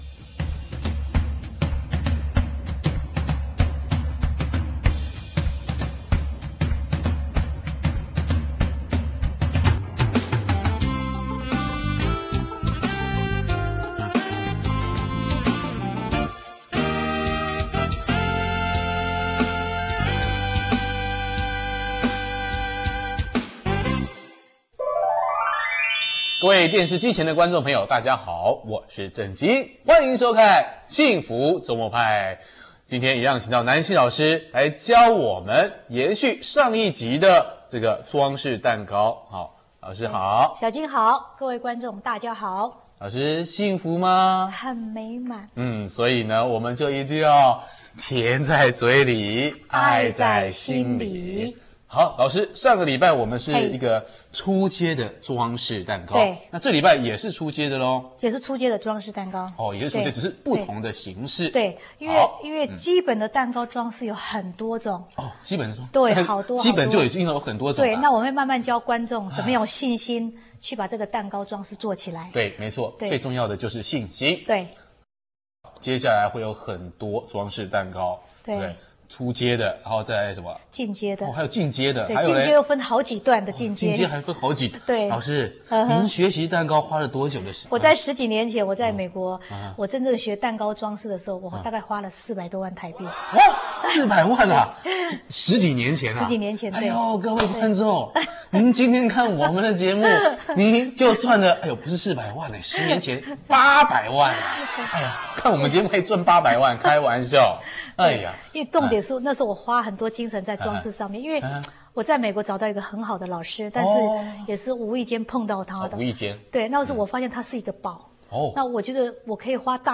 [蛋糕] 幸福週末派--基本蛋糕裝飾(二)(電視教學) - 看板baking